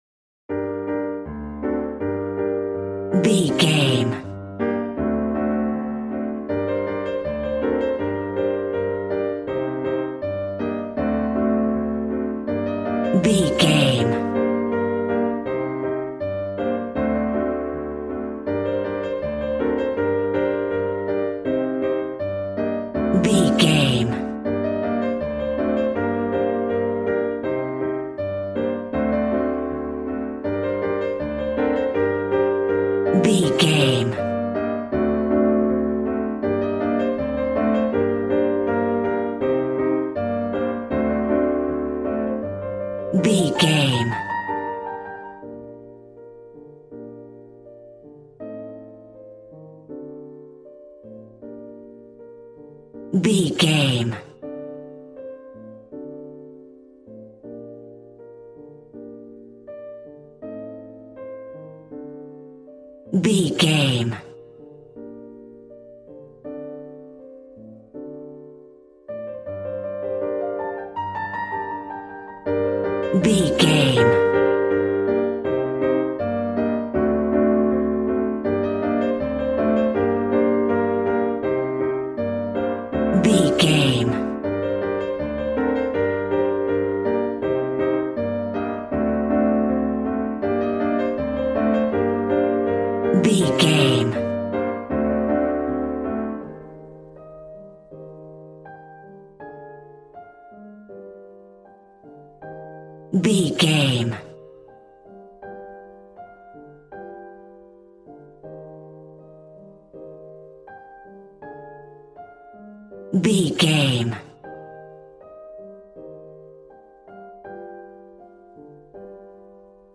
Aeolian/Minor
passionate
acoustic guitar